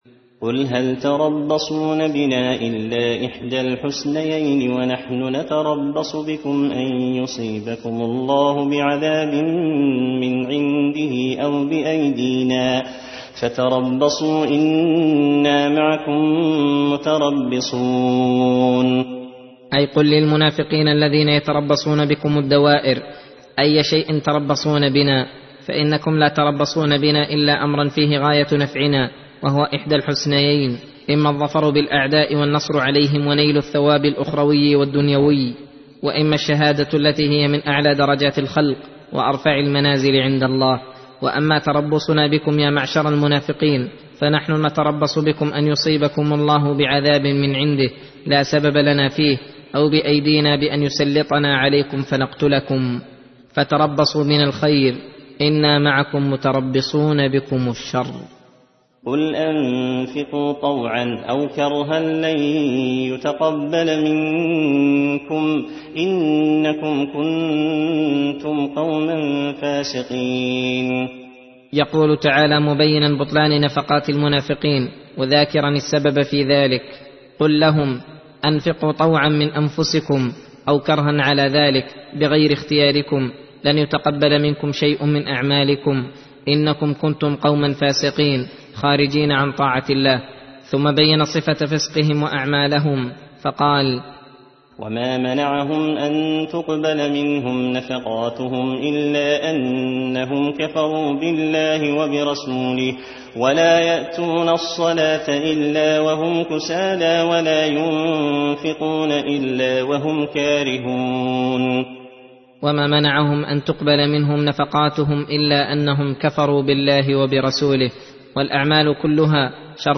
درس (20) : تفسير سورة التوبة (52-66)